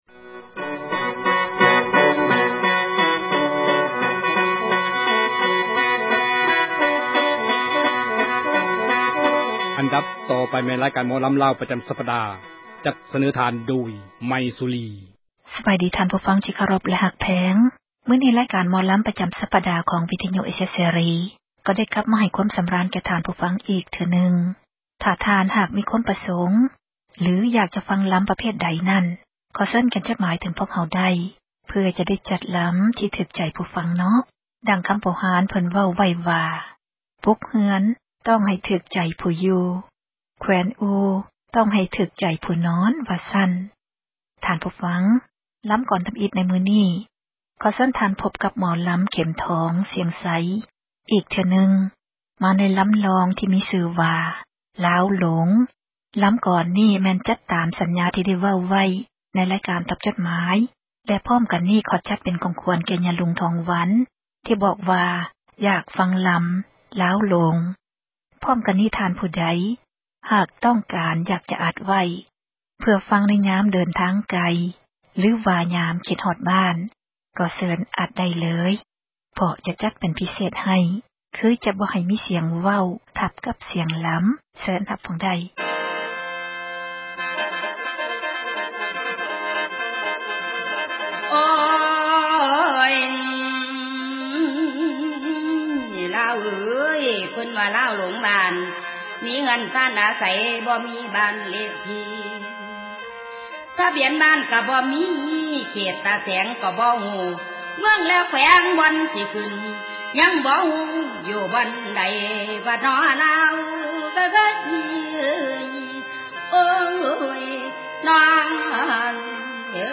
ລຳລ່ອງ